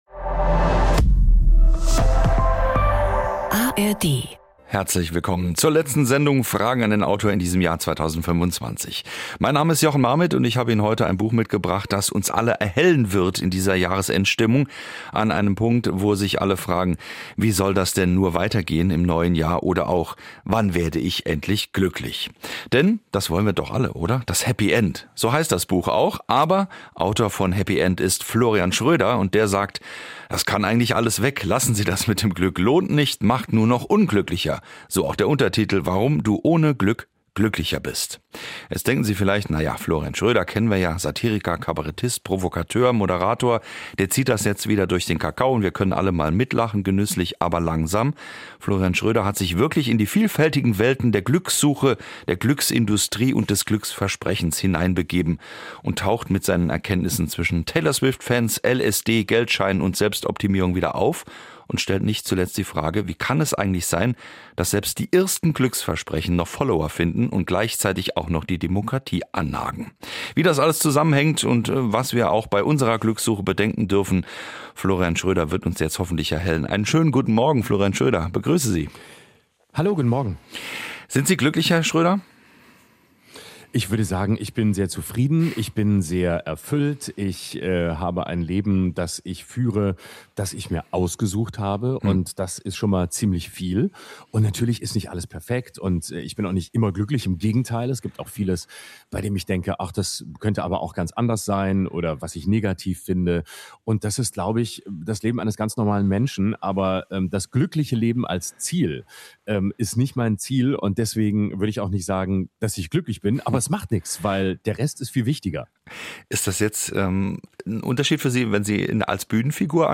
Kabarettist Florian Schroeder